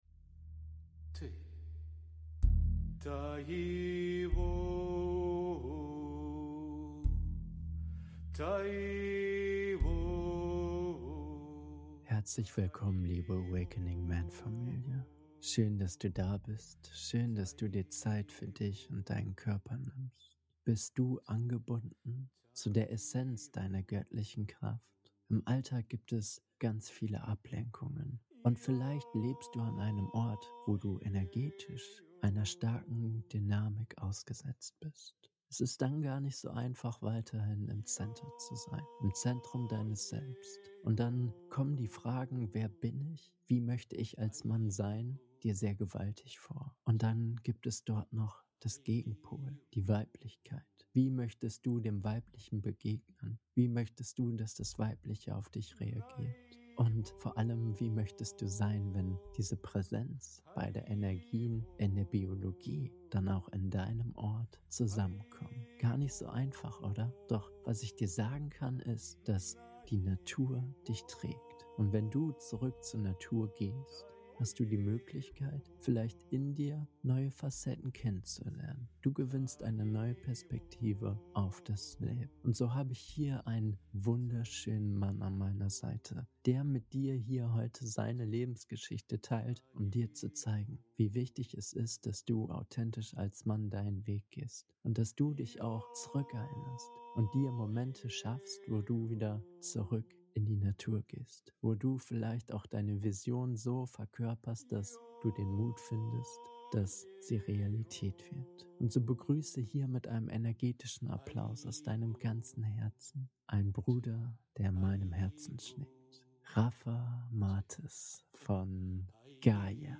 Eine Geschichte der Hingabe - Interview